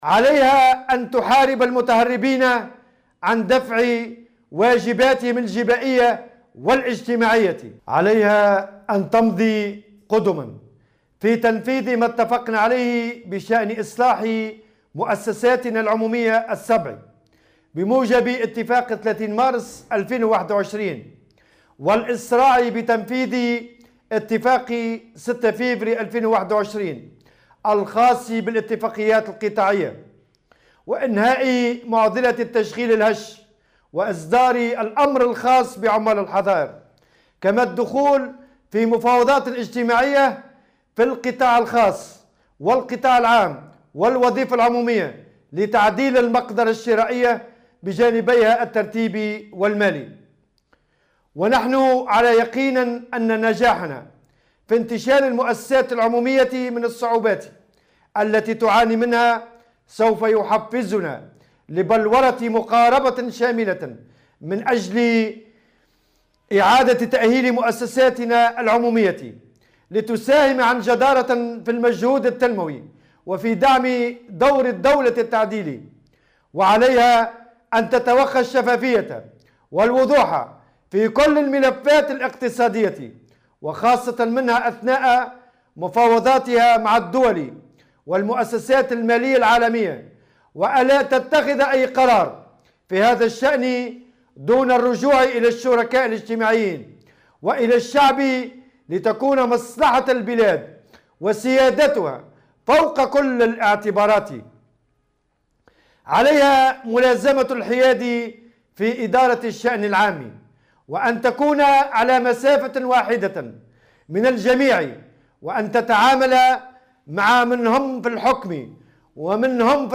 وجّه الأمين العام للاتحاد العام التونسي للشغل، نور الدين الطبوبي، خلال كلمة ألقاها بمناسبة اليوم العالمي للشغل، رسائل إلى الحكومة والبرلمان.